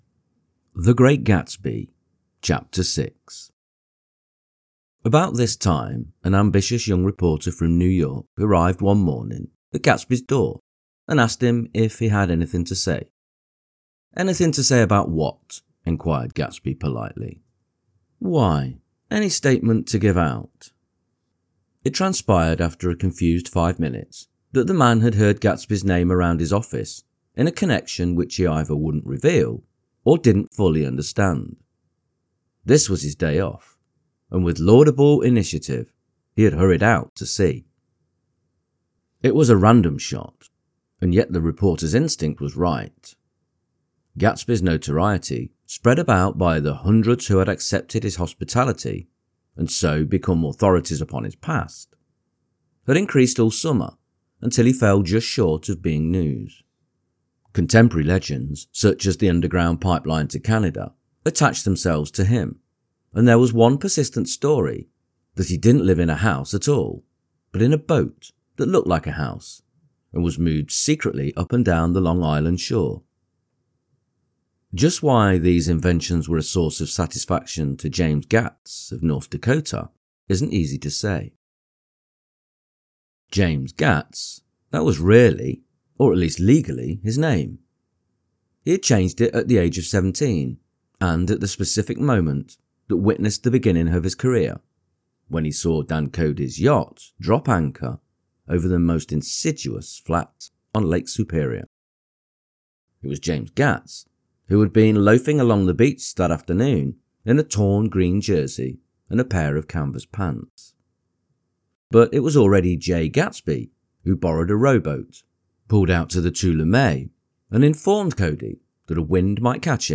The Great Gatsby Audio-book – Chapter 6 | Soft Spoken English Male Full Reading (F.Scott Fitzgerald) - Dynamic Daydreaming